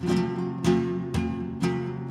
GUITAR LOOPS - PAGE 1 2 3 4
FLAMENCO 1 (189Kb)
Flamenco1.wav